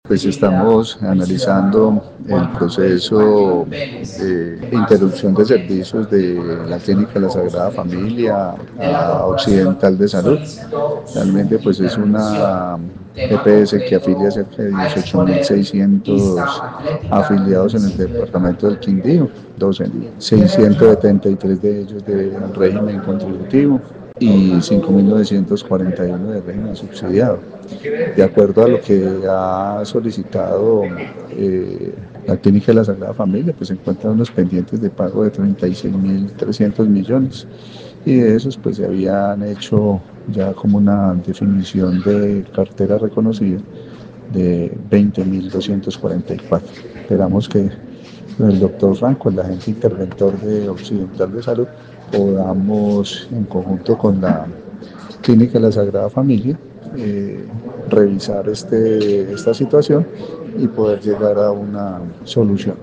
Secretario de Salud del Quindío, Carlos Alberto Gómez